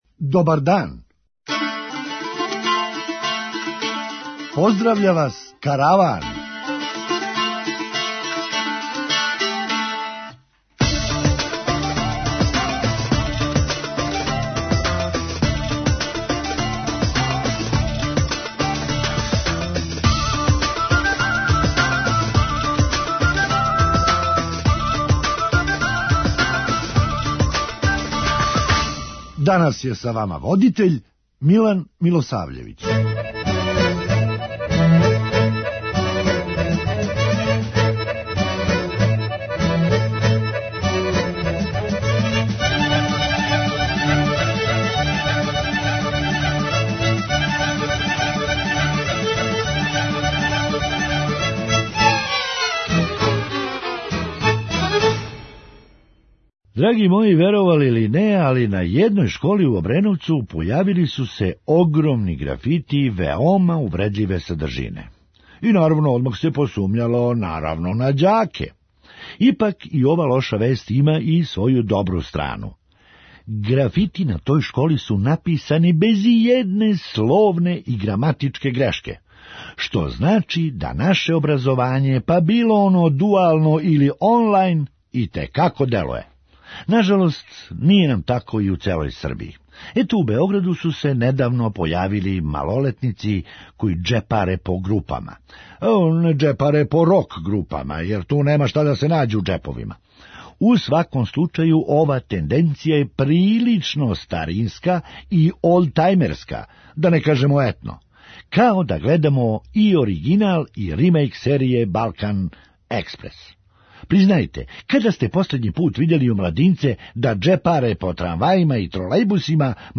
Хумористичка емисија
Није шија него врат...у овом случају није цигла него дрво. преузми : 9.14 MB Караван Autor: Забавна редакција Радио Бeограда 1 Караван се креће ка својој дестинацији већ више од 50 година, увек добро натоварен актуелним хумором и изворним народним песмама.